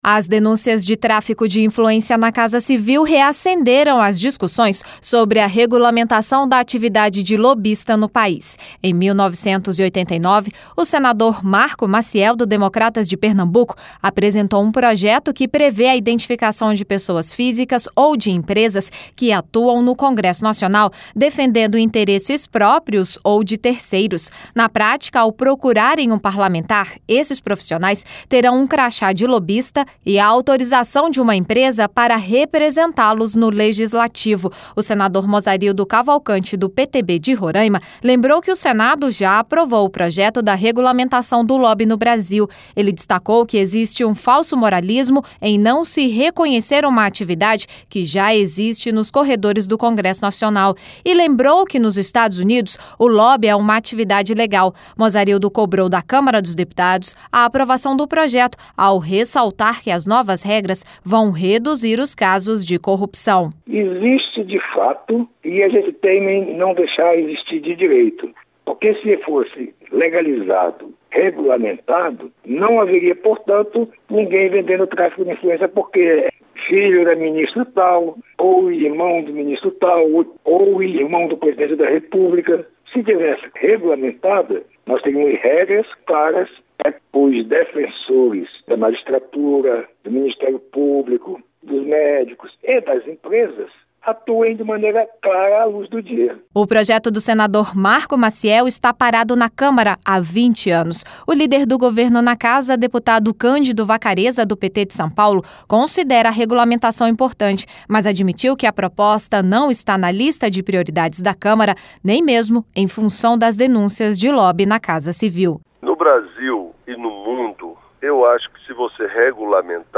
LOC: O LÍDER DO GOVERNO NA CÂMARA ADMITE A IMPORTÂNCIA DA PROPOSTA, MAS ADMITE QUE ELA NÃO É PRIORITÁRIA PARA A CASA.